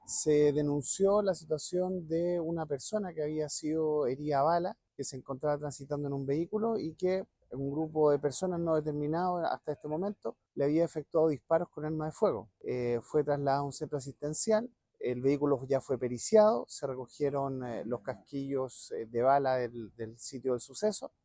El fiscal ECOH, Carlos Eltit, explicó cuáles fueron las primeras diligencias encargadas a la policía.